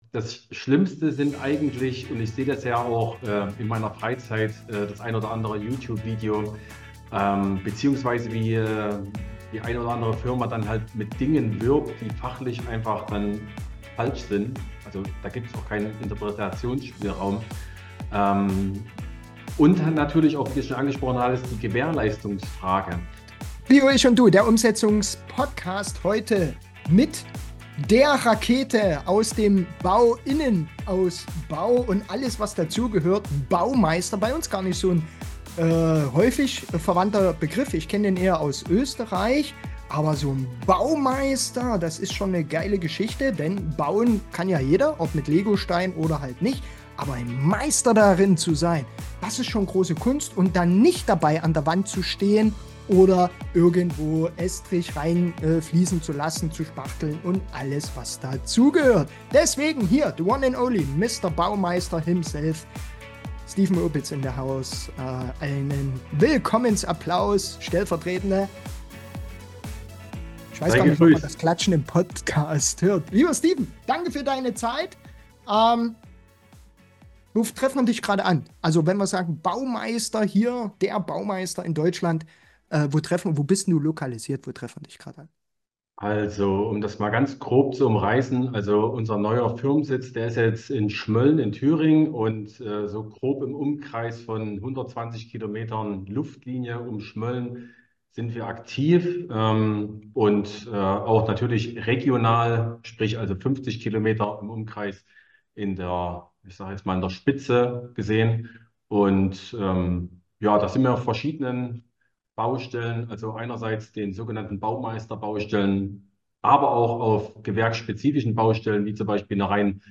Trotzdem bleibt auch für den Laien alles verständlich oder wird noch einmal für „Dummies“ erklärt. Viel Spass beim Einstieg in die neue Interview-Reihe.